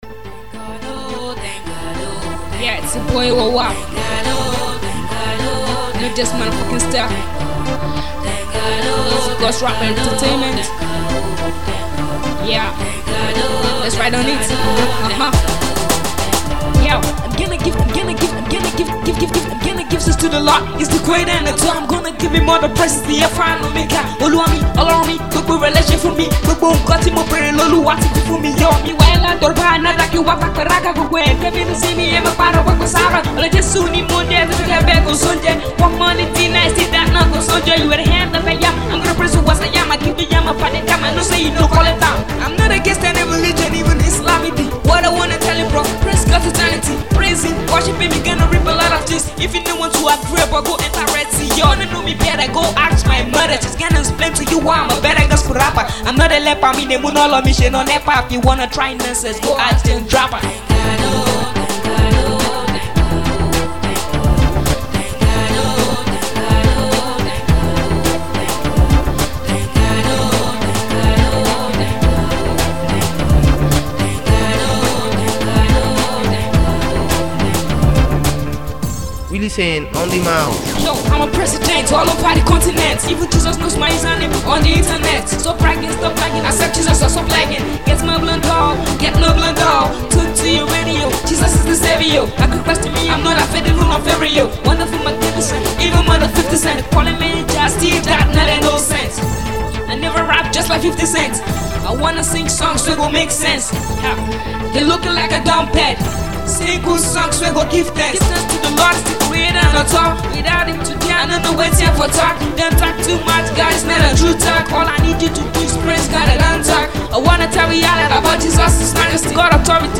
It's highly lyrical.